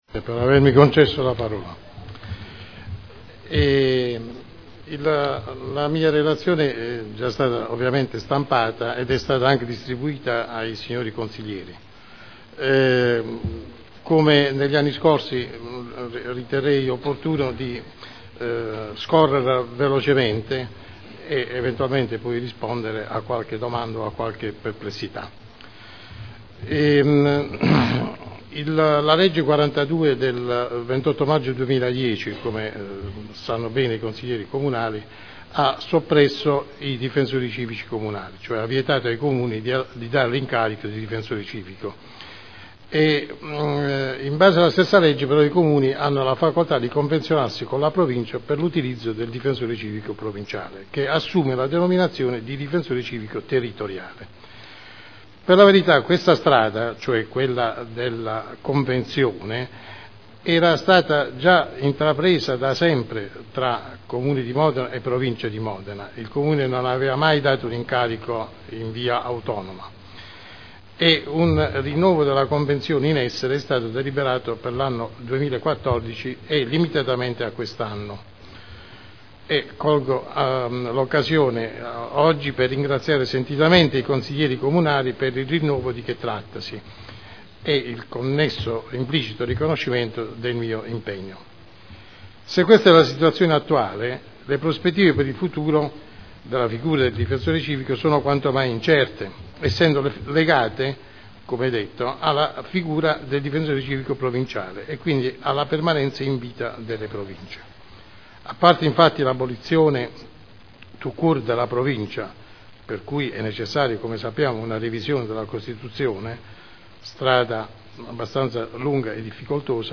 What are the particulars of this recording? Seduta del 24 febbraio. Relazione del Difensore Civico al Consiglio Comunale sull’attività svolta nell’anno 2013